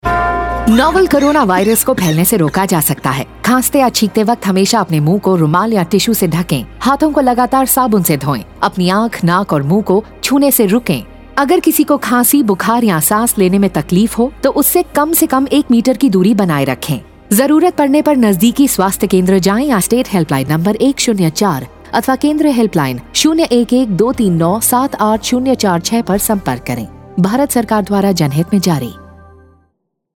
Radio spot on key behaviours COVID-19_Hindi_Uttrakhand
Radio PSA
5147_Cough Radio_Hindi_Uttarakhand.mp3